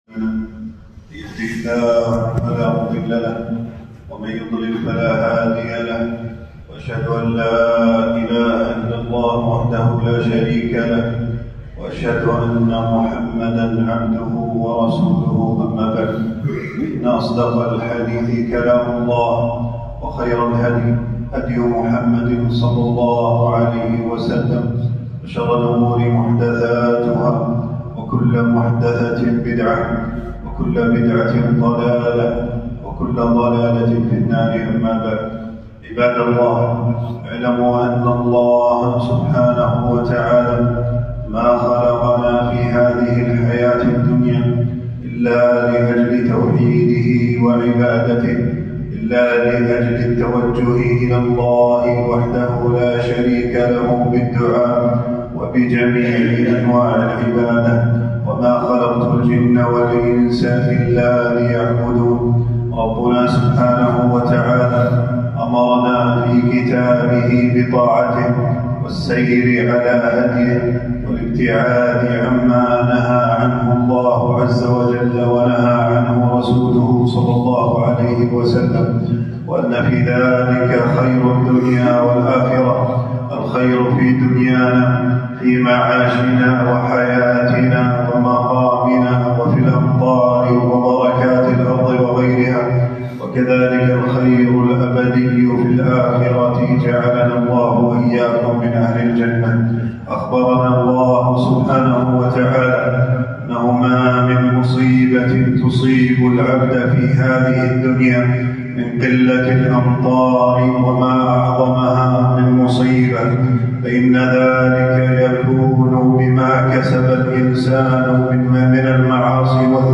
خطبة الاستسقاء
في مسجد الهاشمي، بمدينة المطلاع.